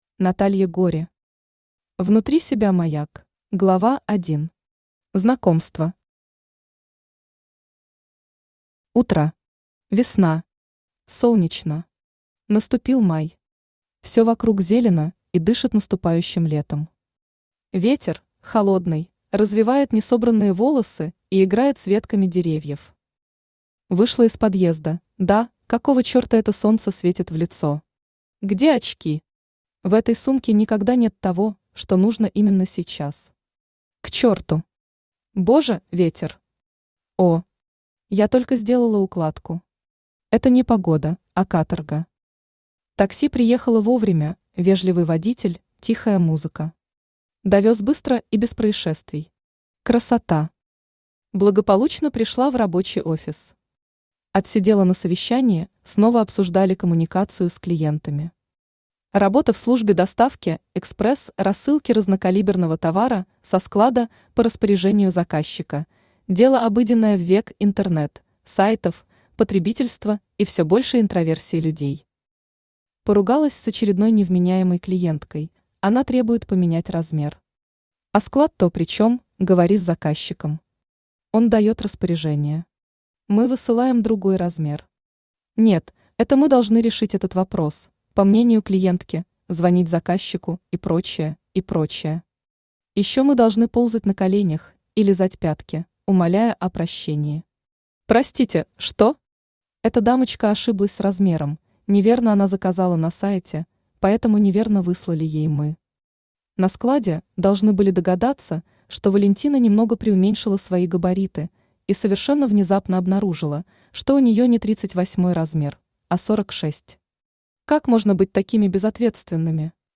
Аудиокнига Внутри себя маяк | Библиотека аудиокниг
Aудиокнига Внутри себя маяк Автор Наталья Юрьевна Гори Читает аудиокнигу Авточтец ЛитРес.